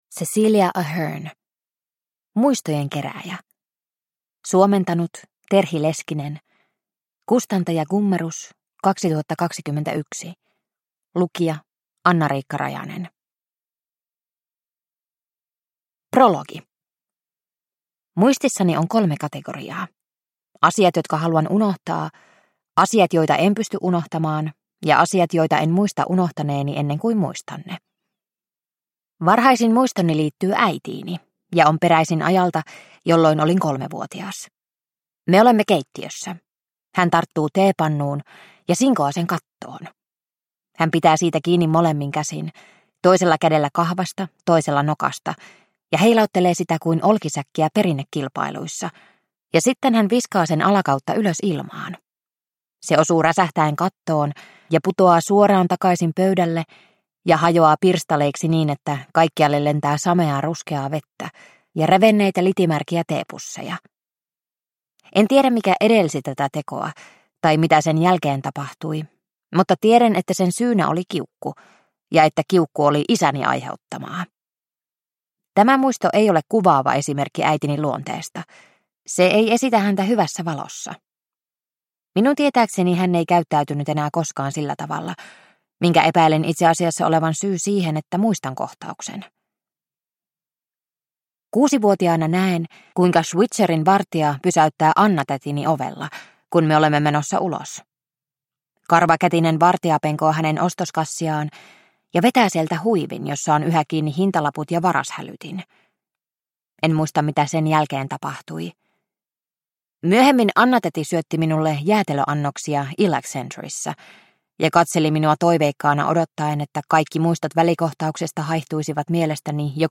Muistojen kerääjä – Ljudbok – Laddas ner